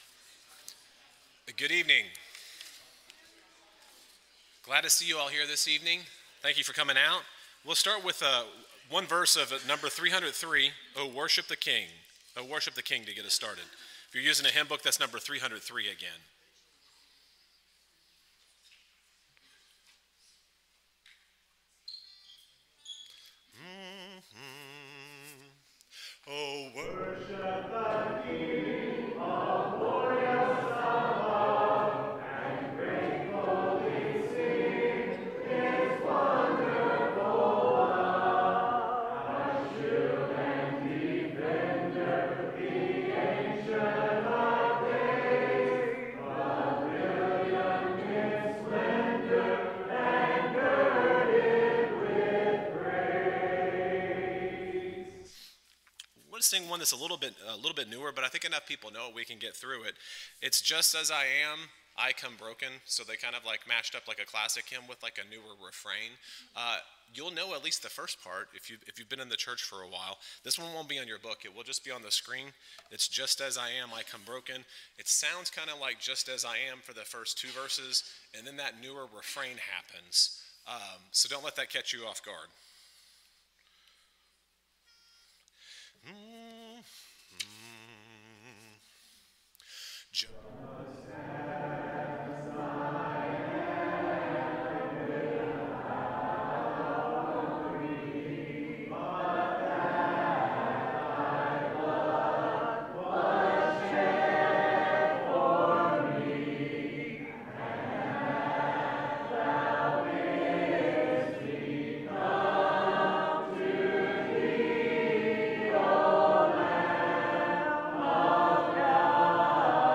Acts 8:36, English Standard Version Series: Sunday PM Service